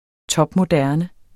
Udtale [ ˈtʌbmoˈdæɐ̯nə ]